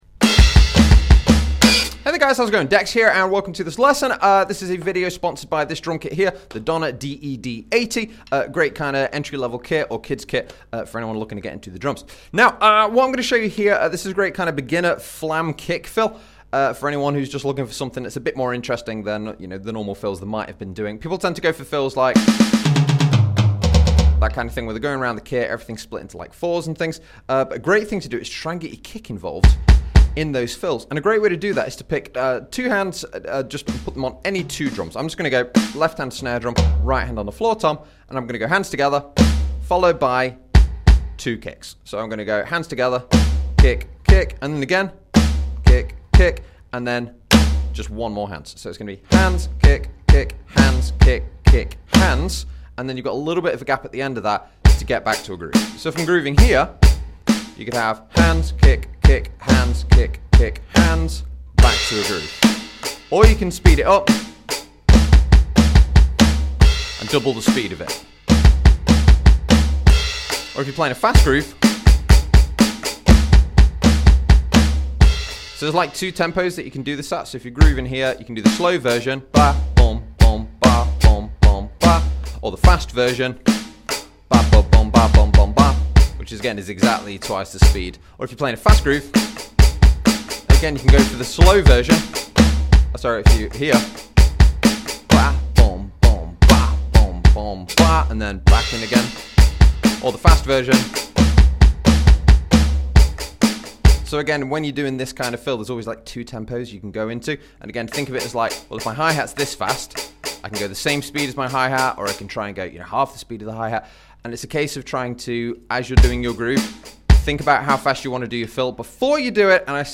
Flam Kick Fill DRUM sound effects free download